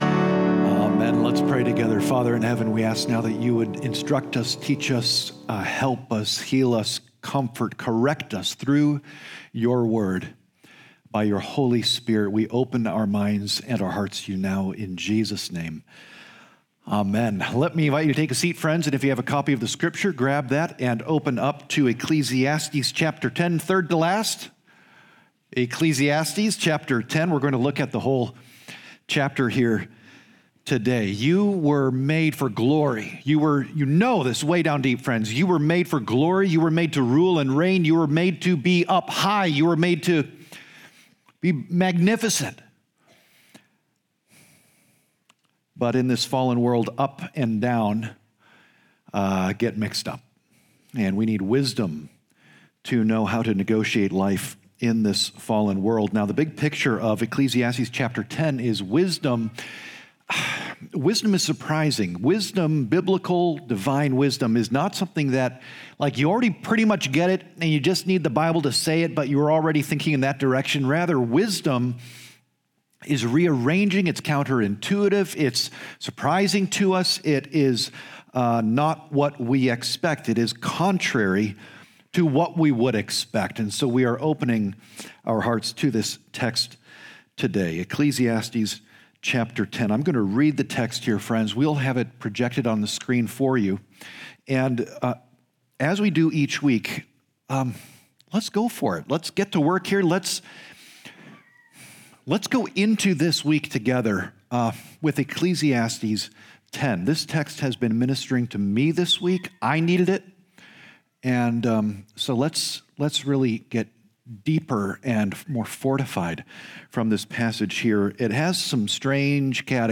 Naperville Presbyterian Church Sermons